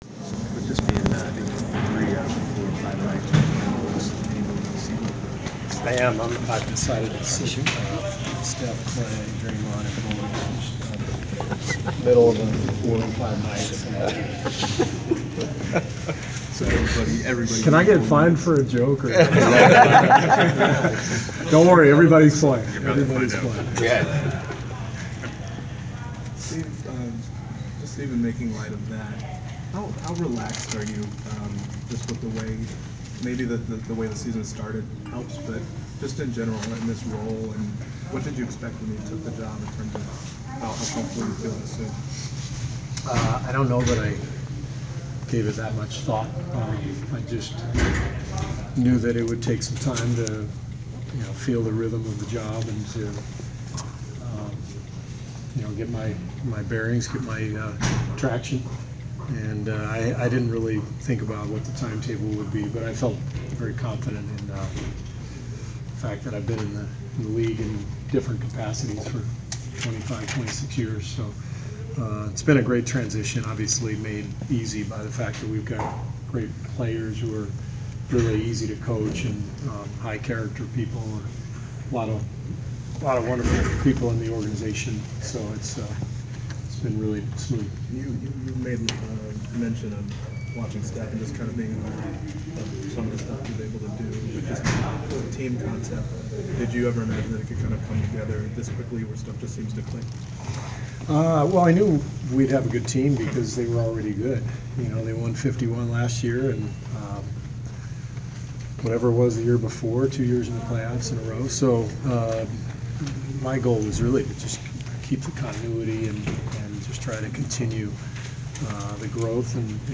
Inside the Inquirer: Pregame presser with Golden State head coach Steve Kerr (2/6/15)
We attended the pregame presser of Golden State head coach Steve Kerr before his team’s road game against the Atlanta Hawks on Feb. 6. Topics included the Warriors’ strong start to the season, defense and overall impression of the Hawks.
steve-kerr-golden-state-pre-hawks.wav